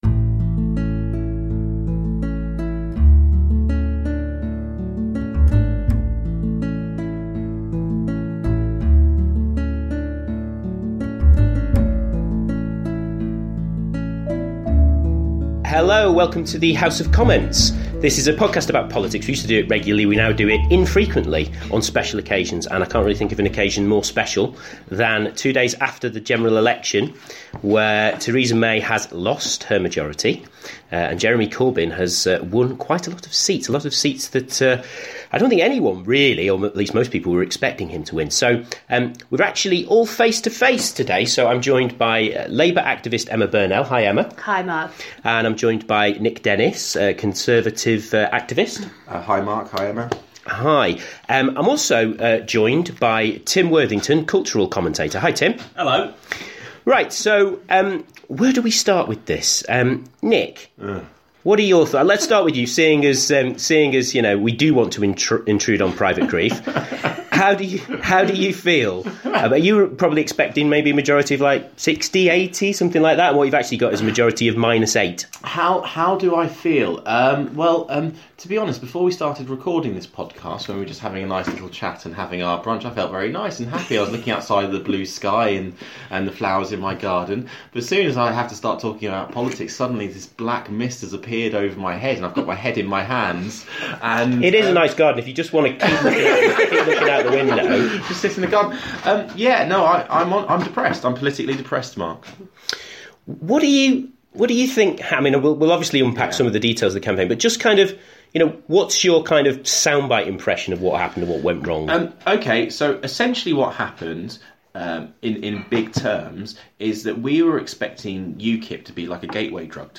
face to face (for the first time ever!)